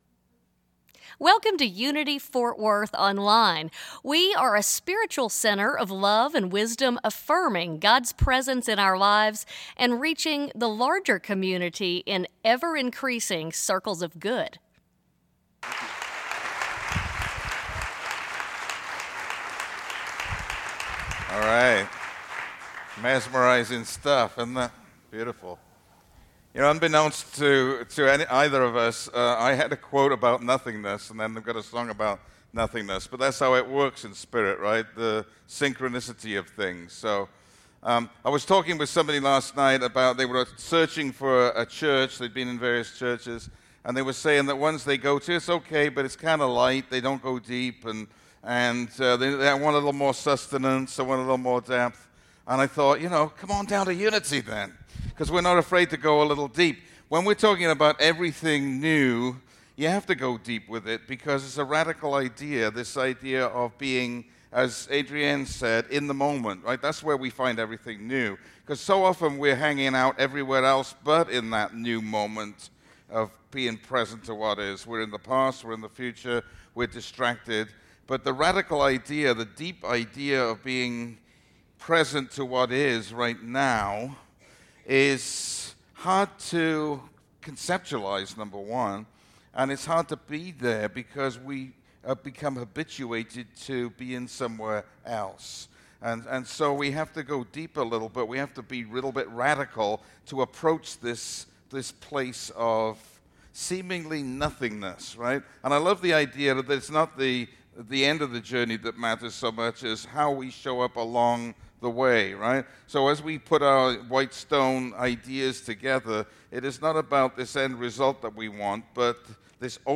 Sermon presented at Unity Fort Worth, Texas